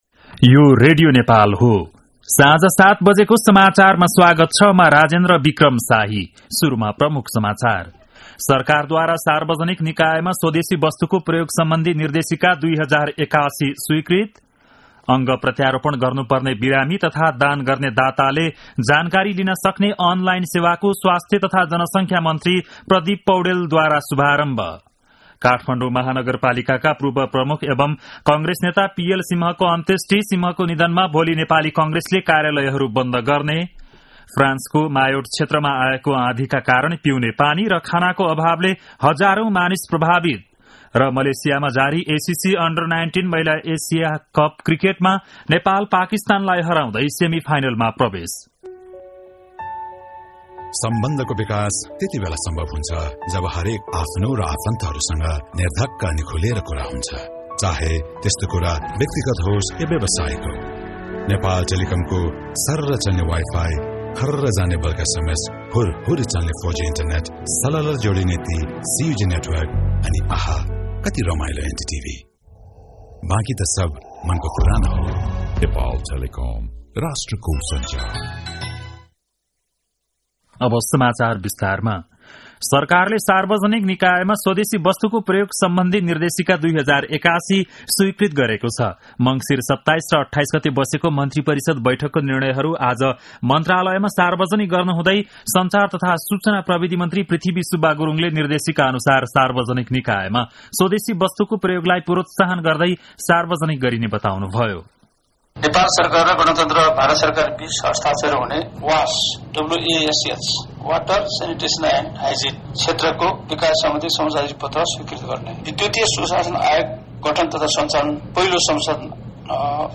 बेलुकी ७ बजेको नेपाली समाचार : २ पुष , २०८१
7-PM-Nepali-News-9-1.mp3